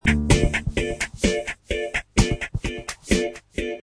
Descarga de Sonidos mp3 Gratis: ritmo 18.